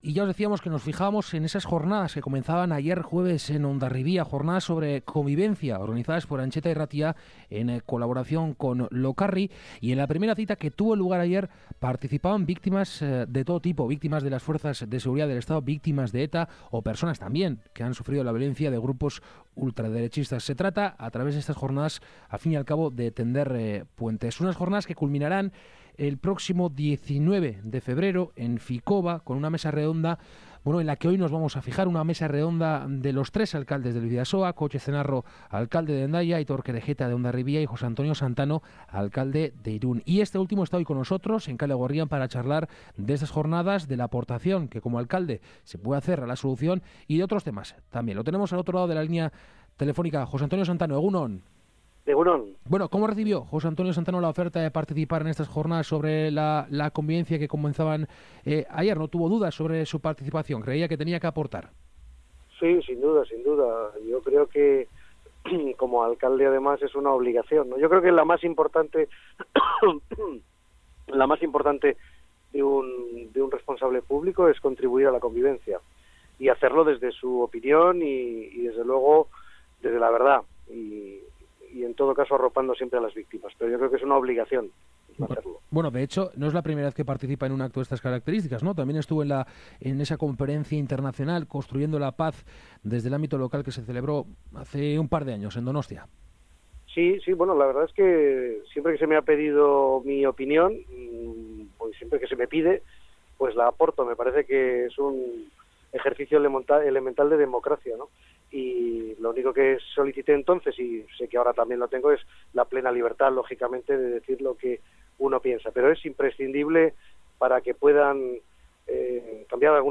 Entrevista con el alcalde de de Irun, Jose Antonio Santano, sobre la cuestión de la convivencia y la resolución al conflicto
Con en este último hemos charlado hoy, en Kalegorrian, sobre la cuestión de la convivencia y la resolución al conflicto.